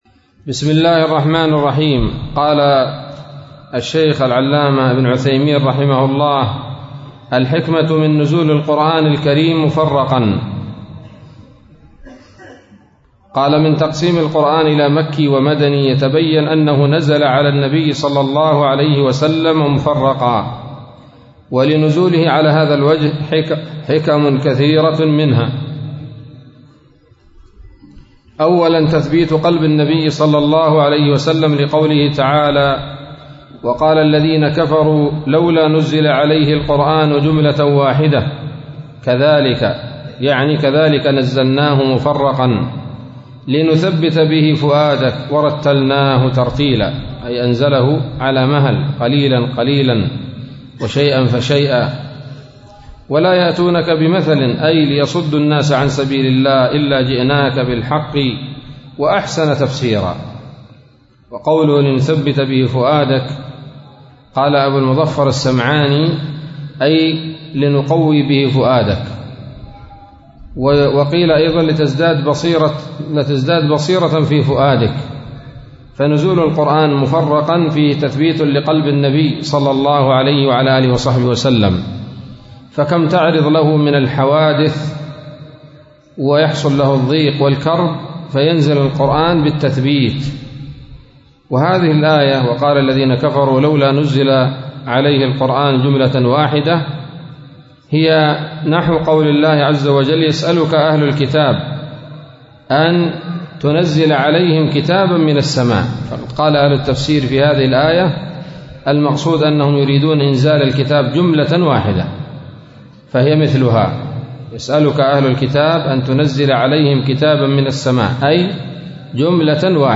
الدرس الثاني عشر من أصول في التفسير للعلامة العثيمين رحمه الله تعالى